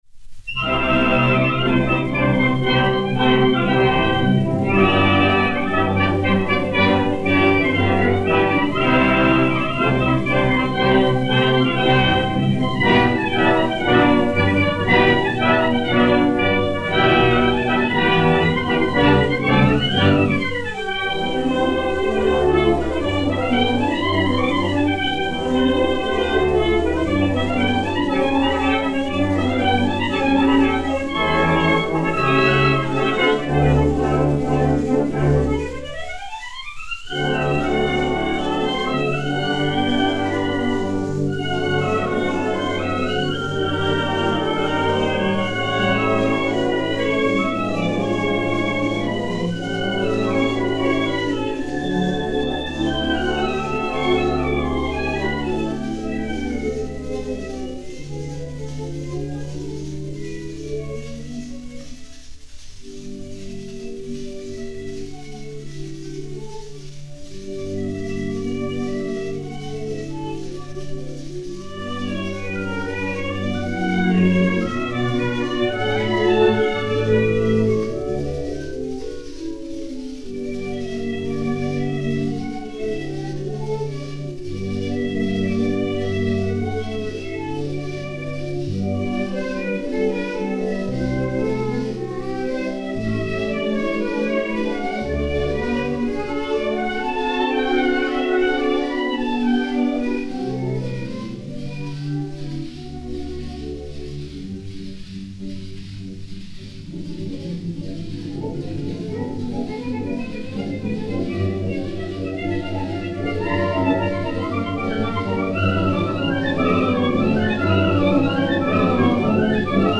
Prélude
Grand Orchestre "Odéon"
KI 2478-3, enr. en 1929